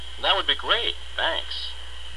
(加連線者為連音，加網底者不需唸出聲或音很弱。)